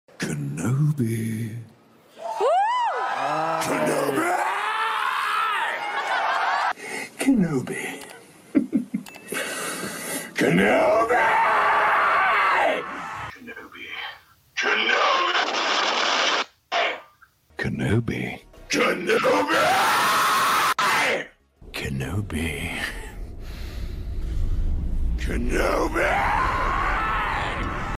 Every Single Time Sam Witwer Has Yelled KENOBI In Star Wars